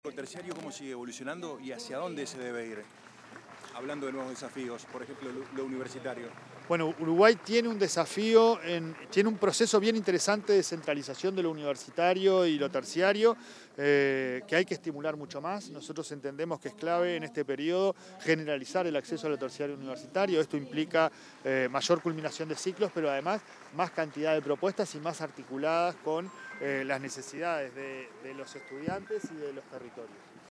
Declaraciones a la prensa del titular de la ANEP, Pablo Caggiani
El presidente de la Administración Nacional de Educación Pública (ANEP), Pablo Caggiani, brindó declaraciones a la prensa luego de participar en el